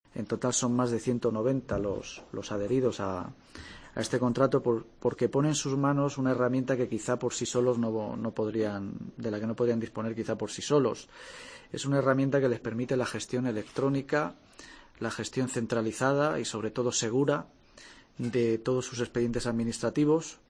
El portavoz del equipo de gobierno provincial, Juan Pablo Martín.